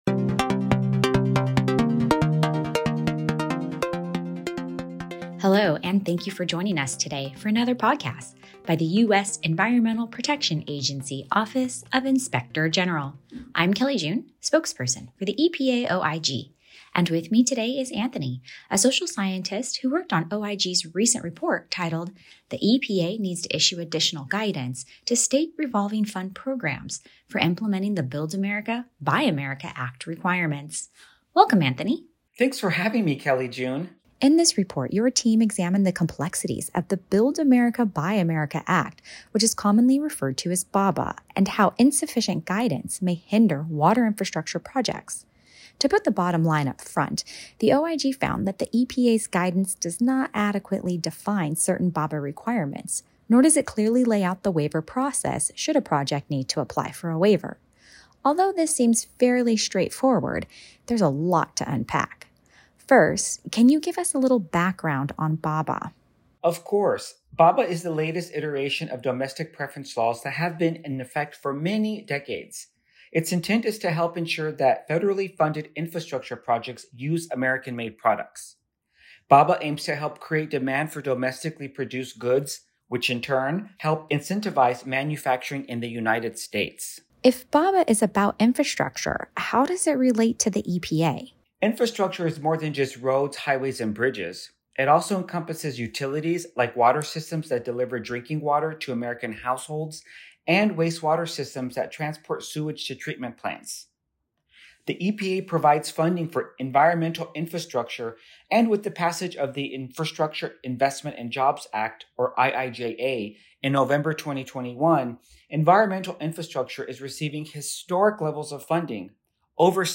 Podcast: Report Overview with EPA OIG auditor (mp3)